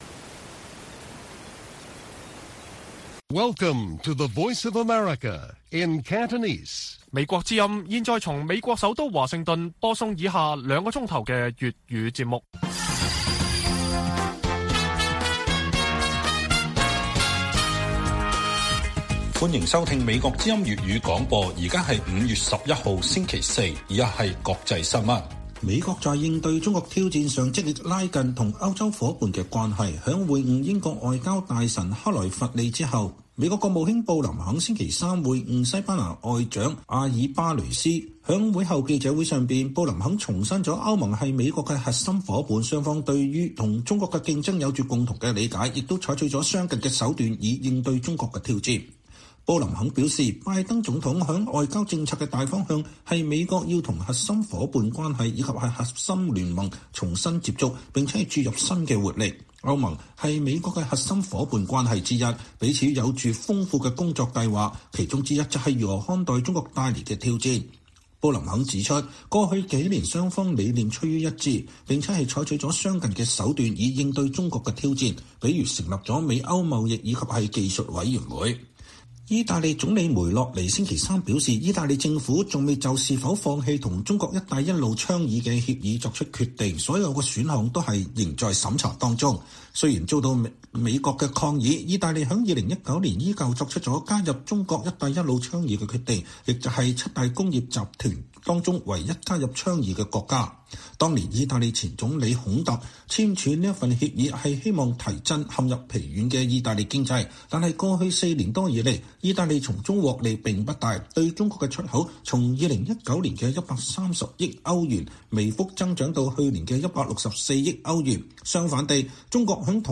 粵語新聞 晚上9-10點: 布林肯說美歐採取相近對策應對中國挑戰